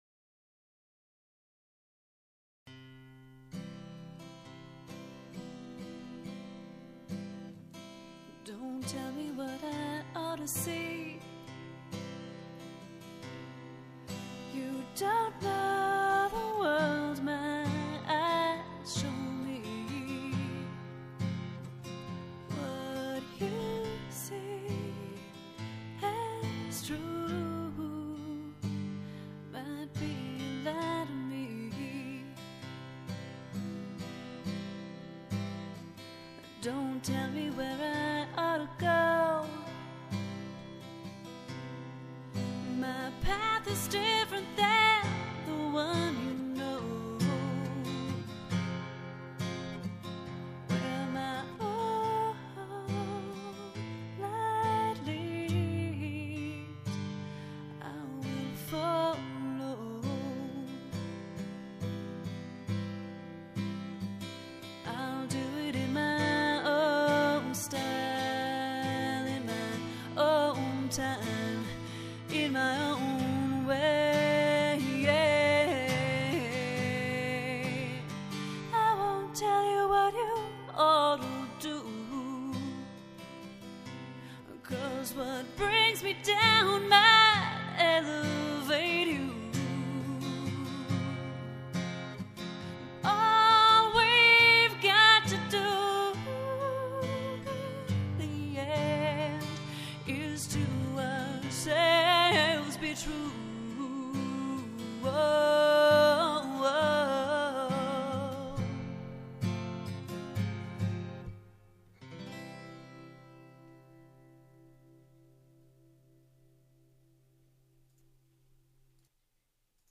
Without any electricity, all I needed was a candle, my acoustic guitar, and an open heart.